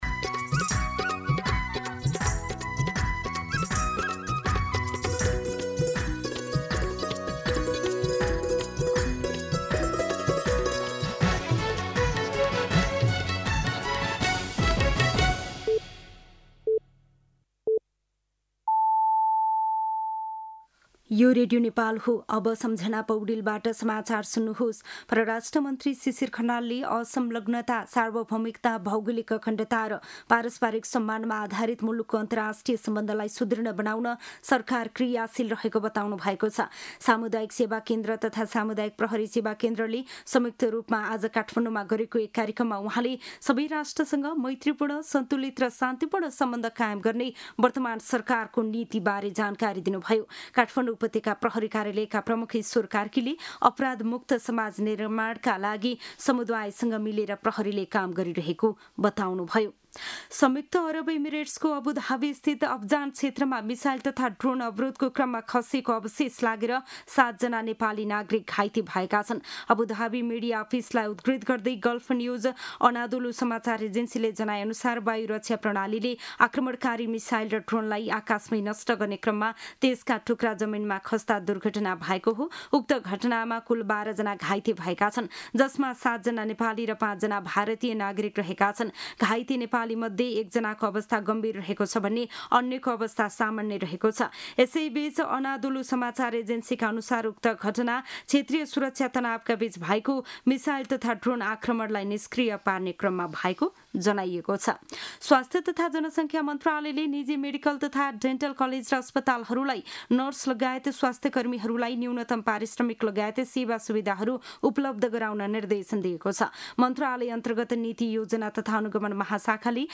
दिउँसो ४ बजेको नेपाली समाचार : २१ चैत , २०८२